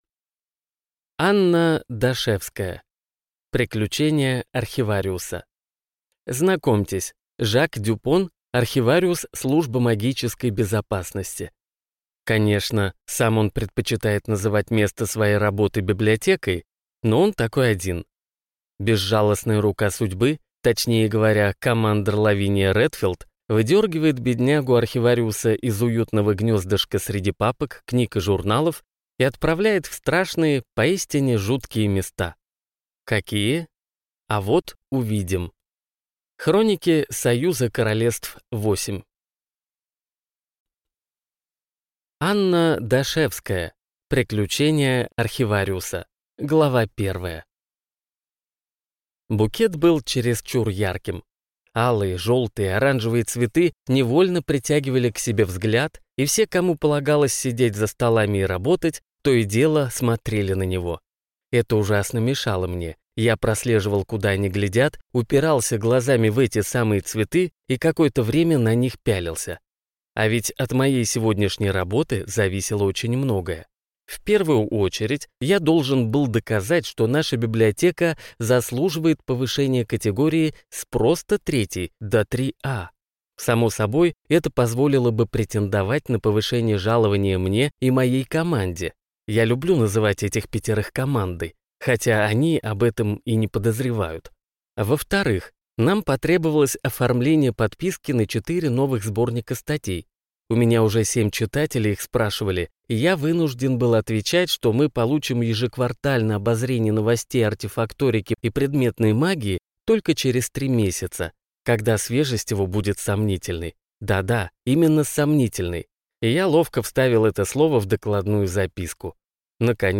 Aудиокнига Приключения архивариуса